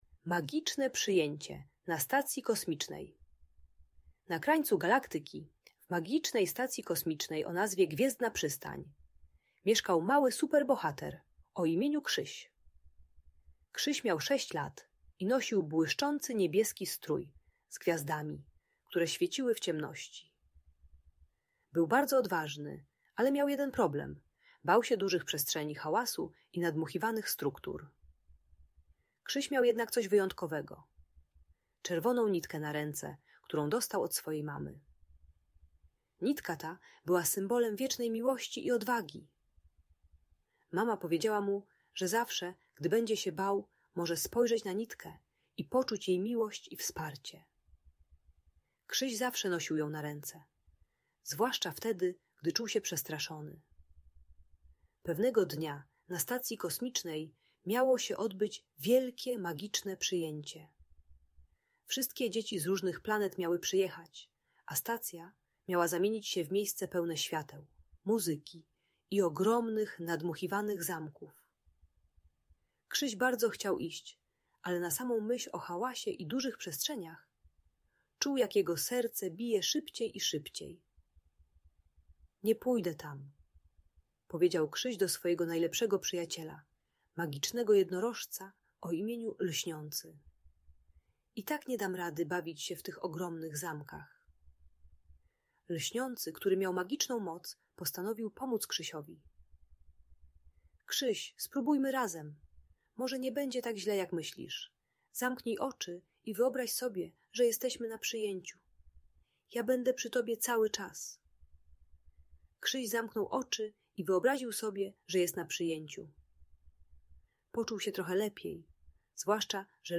Magiczne Przyjęcie na Stacji Kosmicznej - Audiobajka dla dzieci